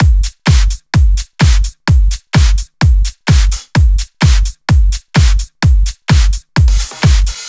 VDE 128BPM Silver Drums 1.wav